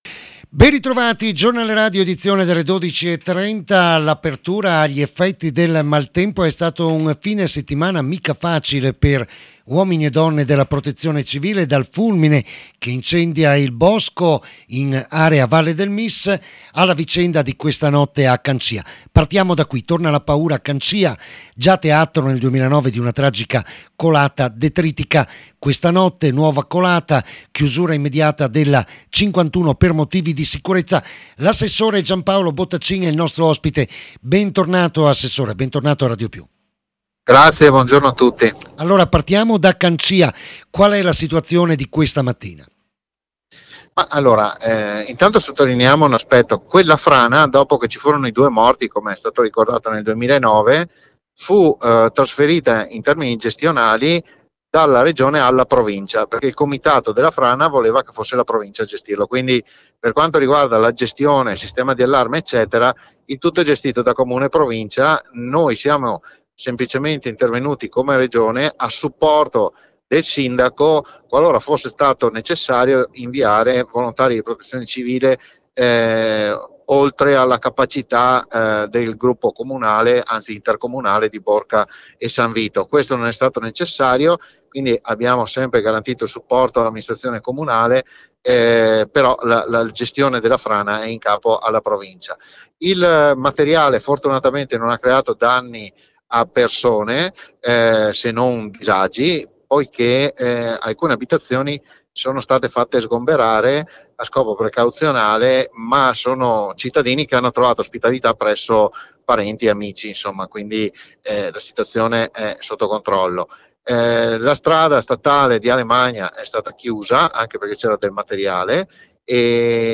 Un fine settimana impegnativo per uomini e donne della protezione civile, ai microfoni di RADIOPIU l’assessore Gianpaolo Bottacin.